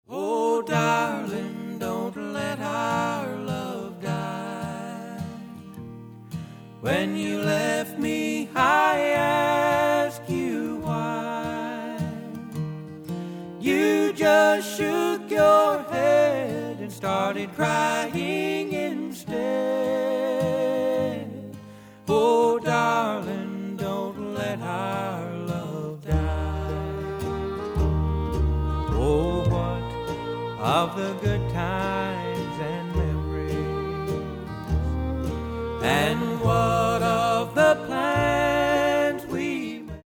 lead vocal
tenor